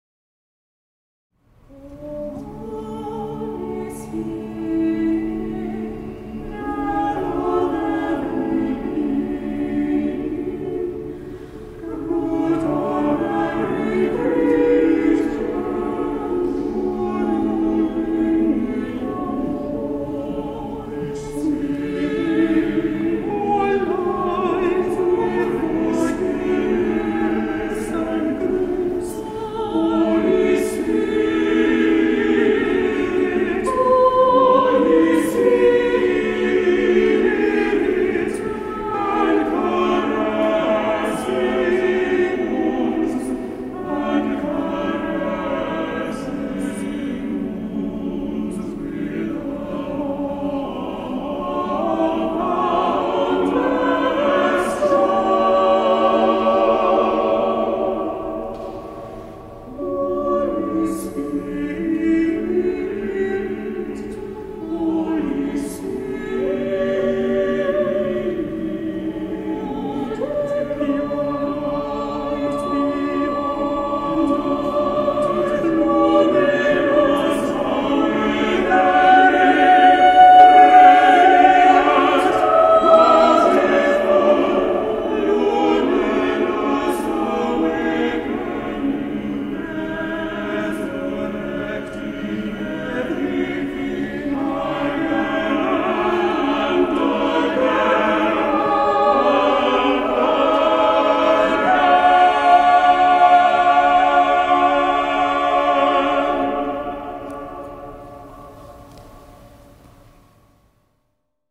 A bountiful and expansive setting
SATB a cappella